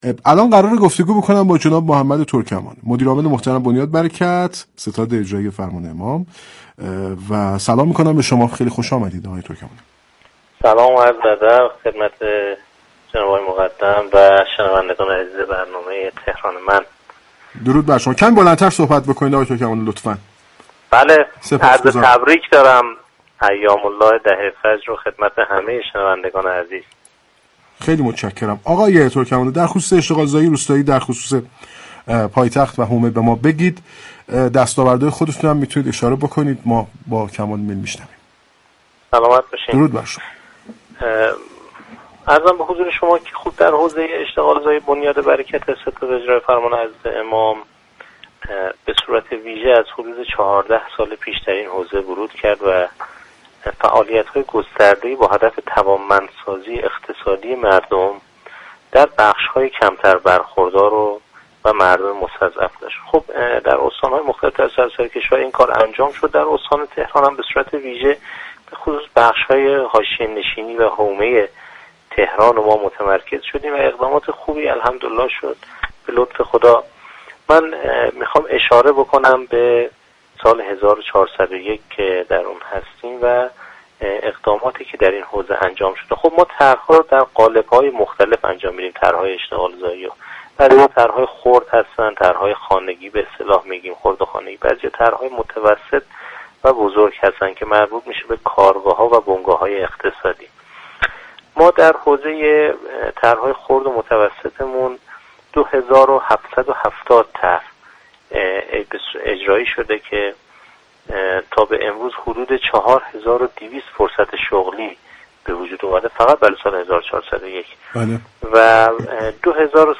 گفت و گو با «تهران من»